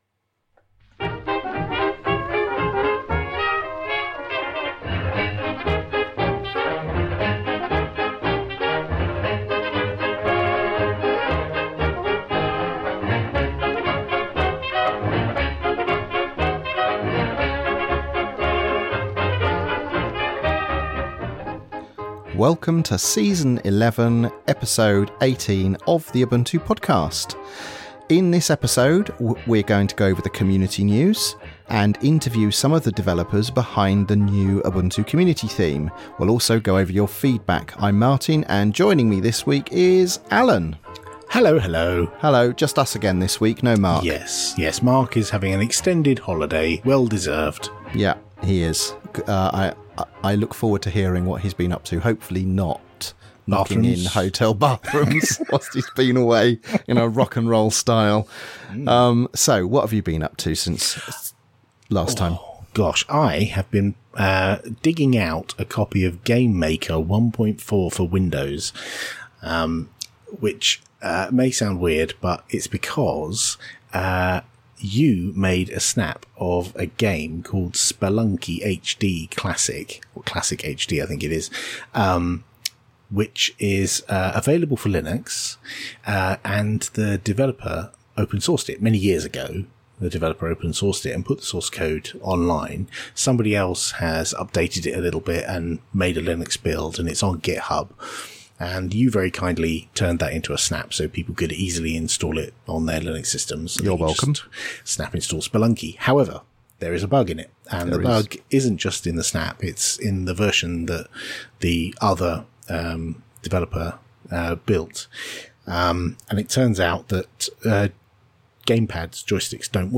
This week we’ve been using GameMaker 1.4 on Windows to patch Spelunky for Linux. We interview some of the Ubuntu Communitheme team, round up the community news and go over your feedback.